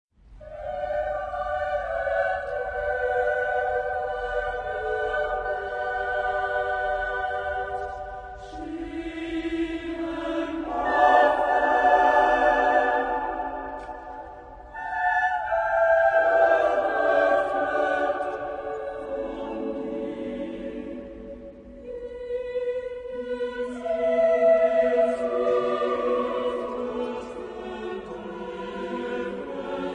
Genre-Style-Forme : Motet ; Sacré
Caractère de la pièce : calme ; lent
Type de choeur : SSAATTBB  (8 voix mixtes )
Tonalité : ré (centré autour de)